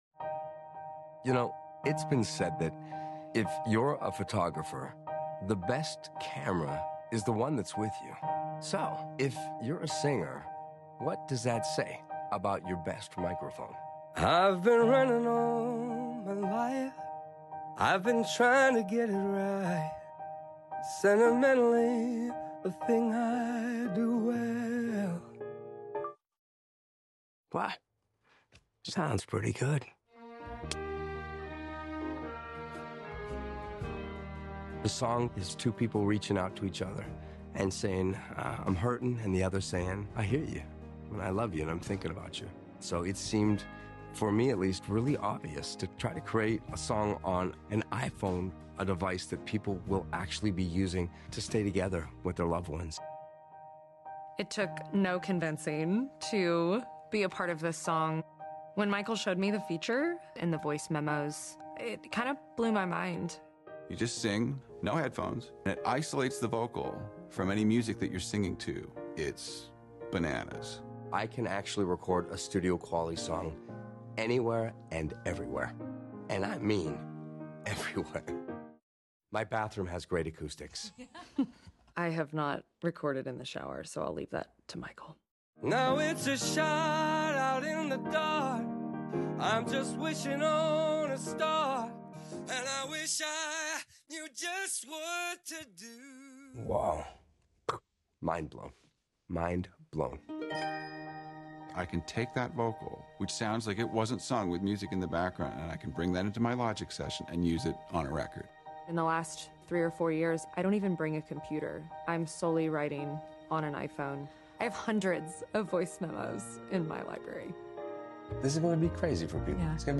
recorded on iPhone 16 Pro with Voice Memos.